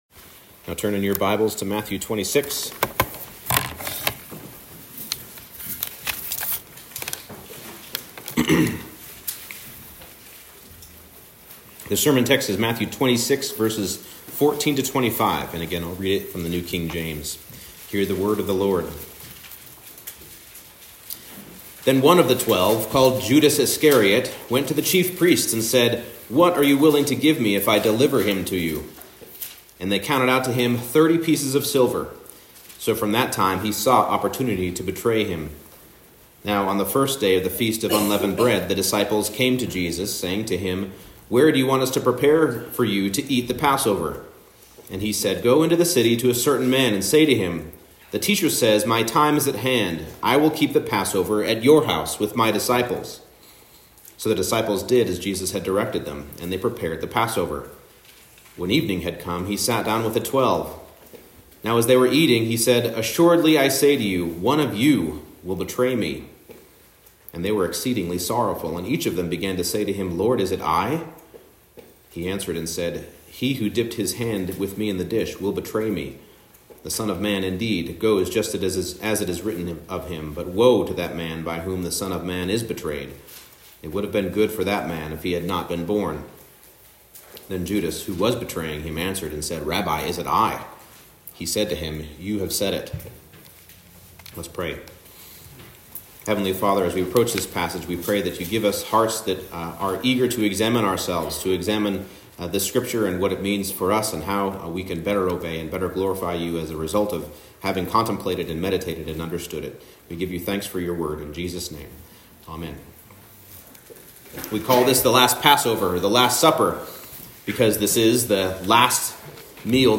Service Type: Special Worship Service